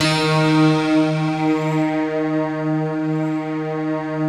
SI1 PLUCK05R.wav